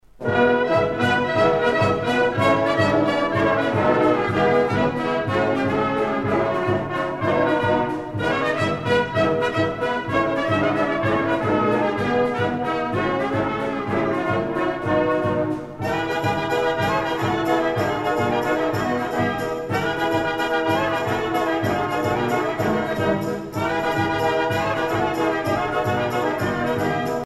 danse : horo (Bulgarie)
Pièce musicale éditée